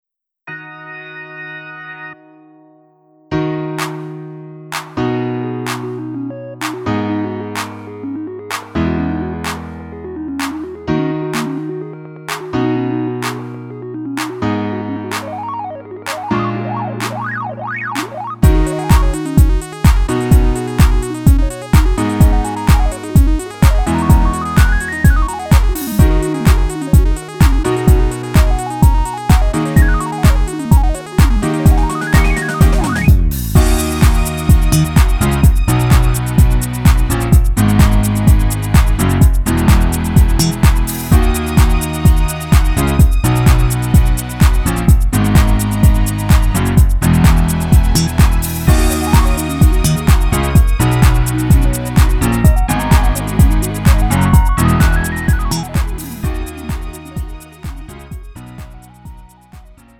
음정 원키 3:09
장르 구분 Lite MR